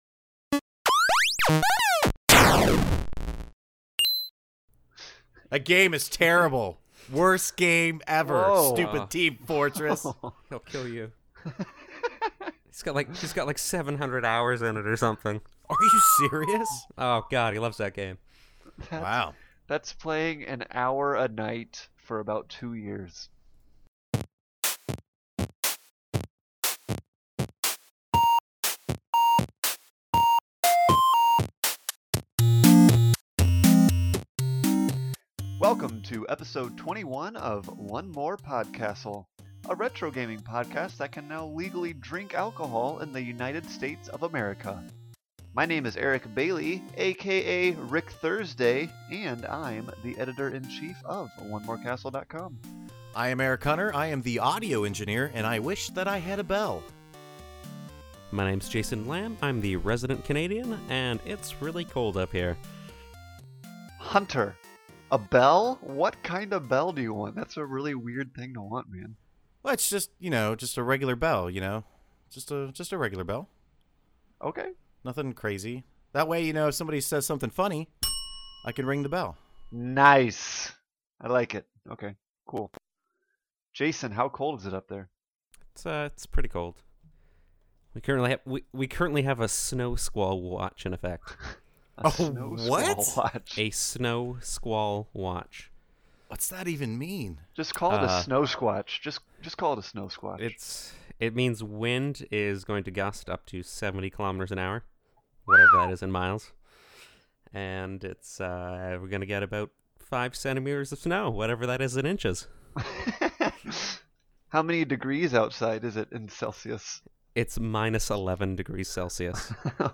(DING!)